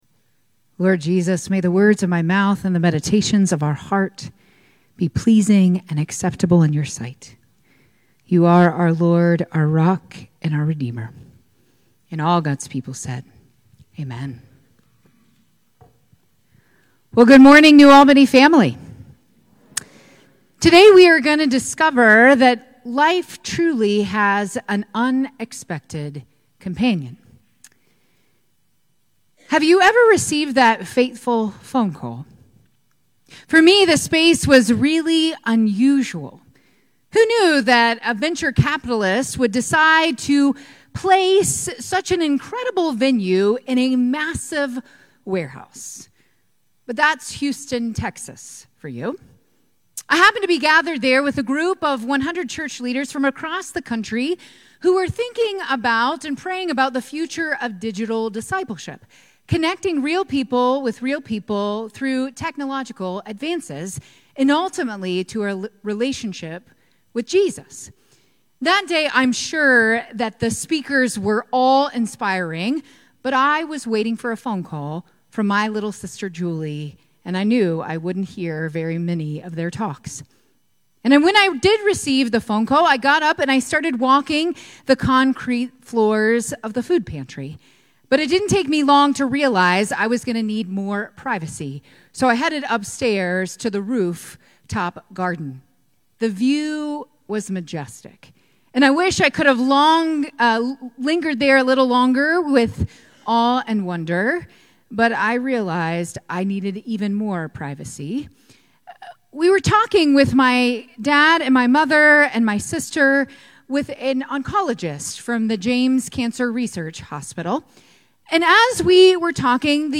9:30 AM Contemporary Worship Service 03/30/25